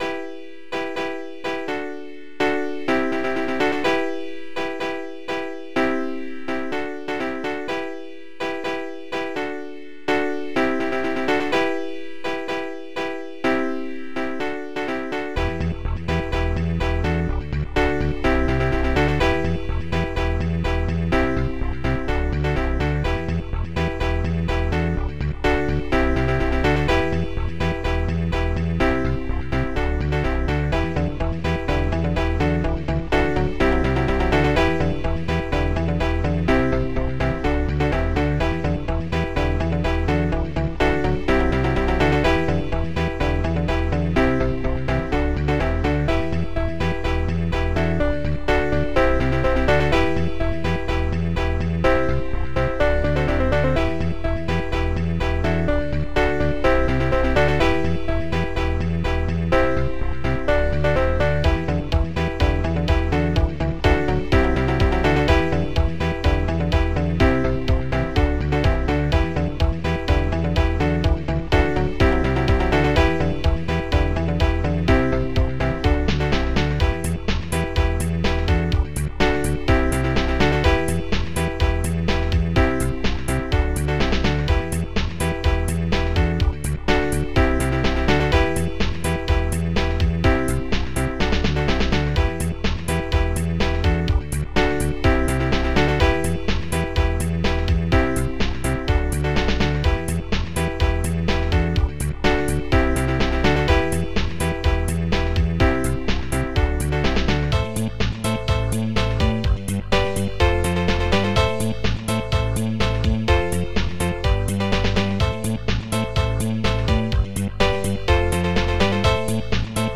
Protracker Module  |  1987-04-22  |  146KB  |  2 channels  |  44,100 sample rate  |  6 minutes, 8 seconds
st-04:guitar7
st-17:s220-piano.maj
st-08:drum-elec.twi
st-04:highhat2
st-11:stringc
st-11:organa